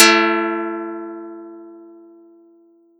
Audacity_pluck_8_13.wav